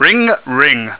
mužský hlas 0:02